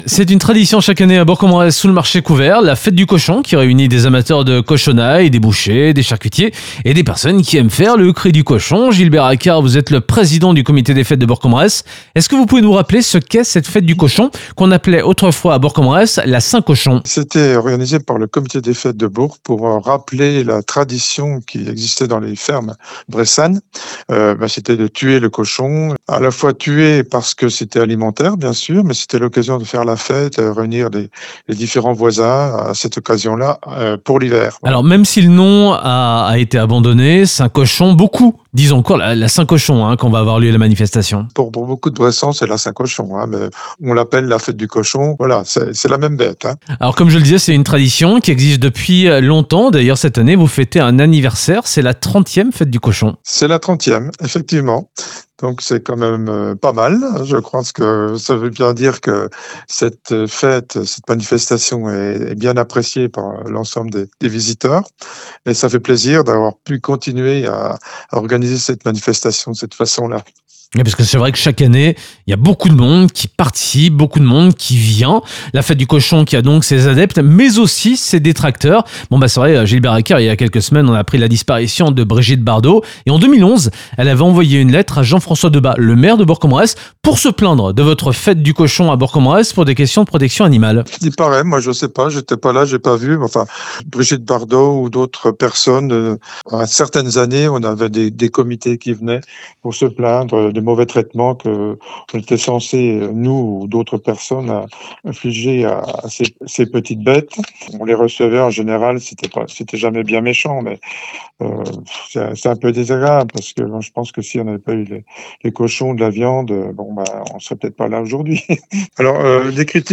3. Interview de la Rédaction